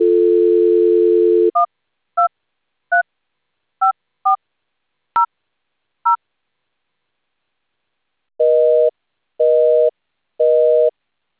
telephony signaling examples
Telephony protocol sounds
1busy.wav